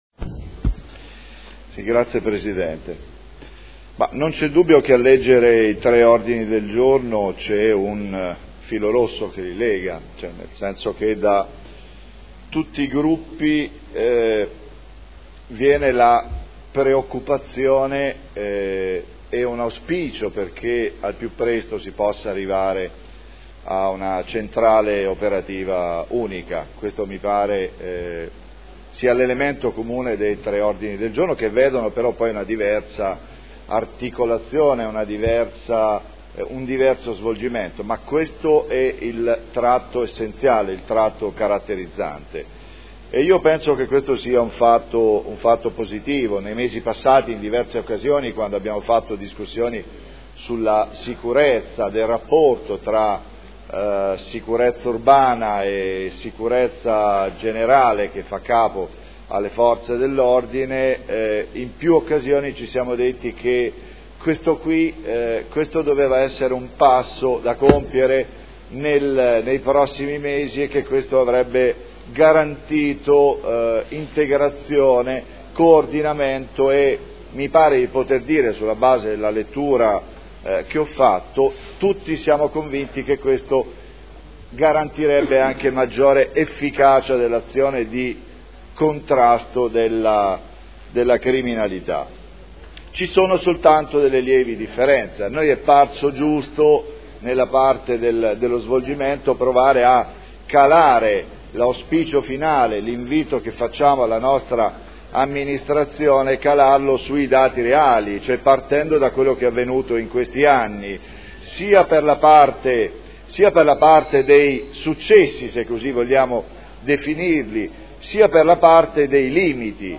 Seduta del 23/05/2011. Dibattito sui tre ordini del giorno sulla Centrale Operativa Unica delle forze di polizia modenesi.